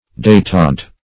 detente \detente\ (d[asl]*t[aum]nt"), n.